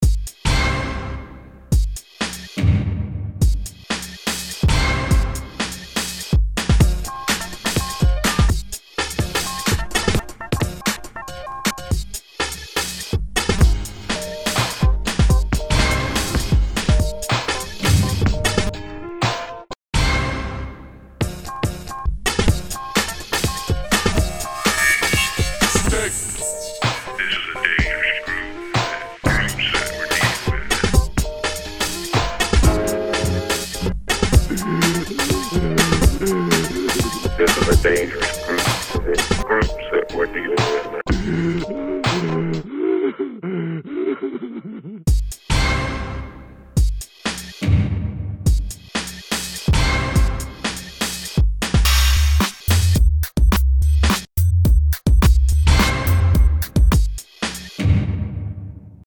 Vocals, Art Direction, Band
Synthesizer, L.S.I., Band
Keyboards, Uillean Pipes, Band